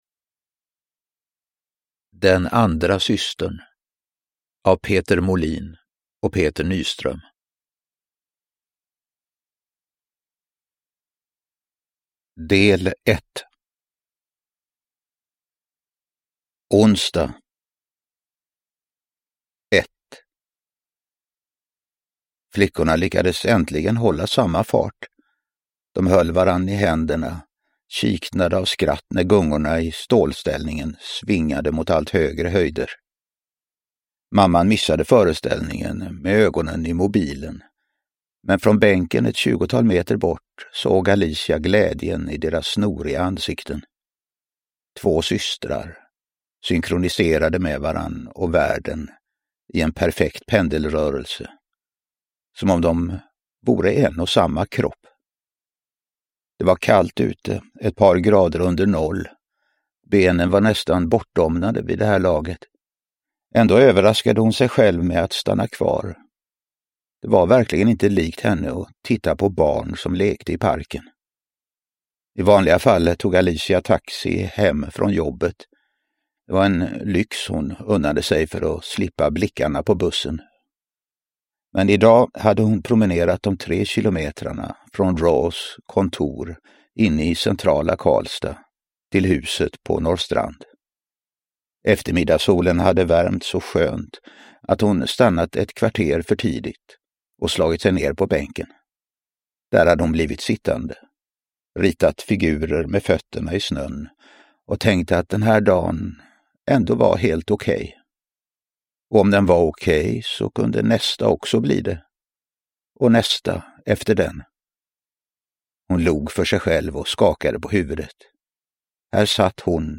Den andra systern – Ljudbok – Laddas ner
Uppläsare: Reine Brynolfsson